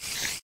snd_spidershoot.ogg